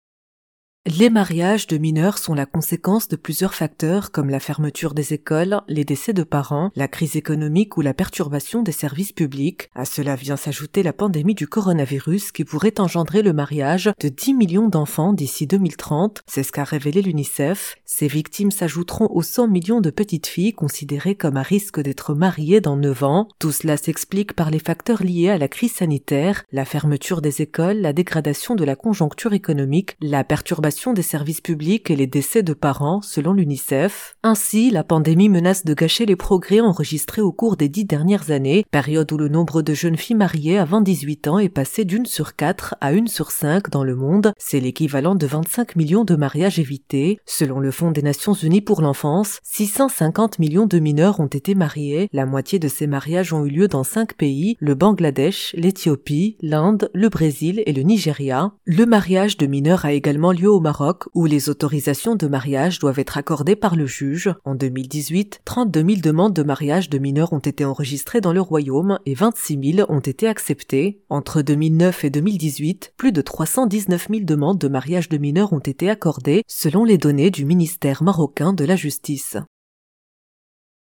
Article à écouter en podcast